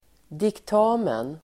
Ladda ner uttalet
Uttal: [dikt'a:men]